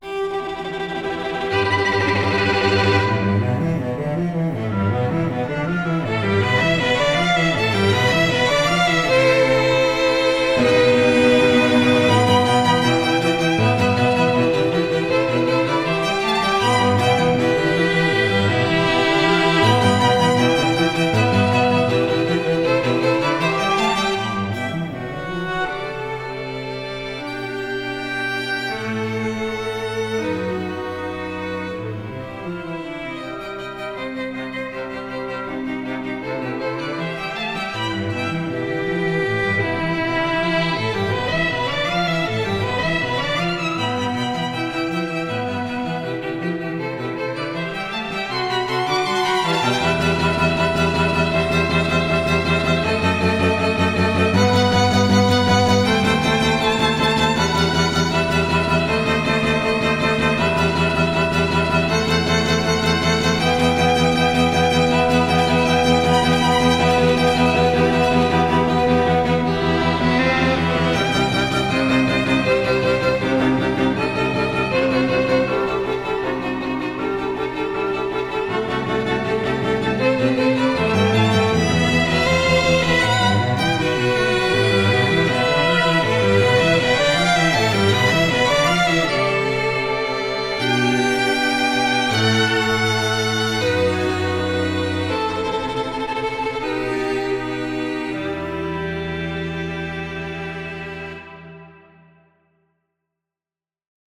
For Strings
CrAttitude-Strings.mp3